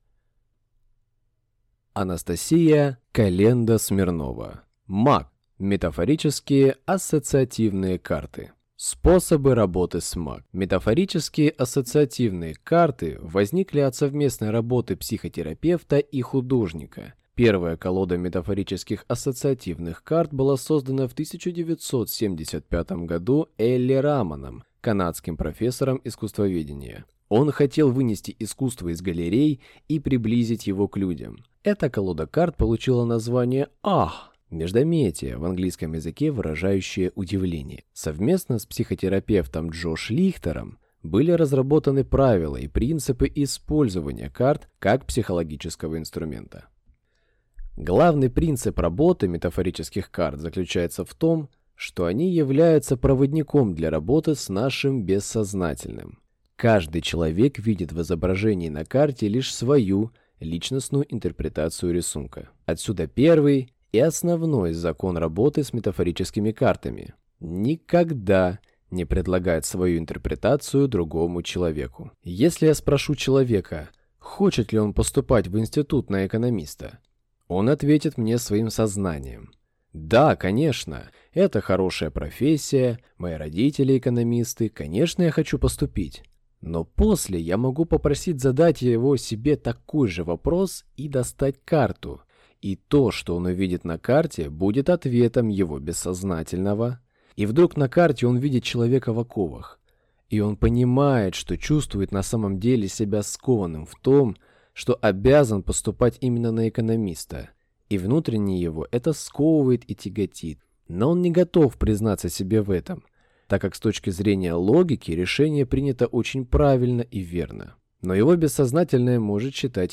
Аудиокнига МАК (метафорические ассоциативные карты) | Библиотека аудиокниг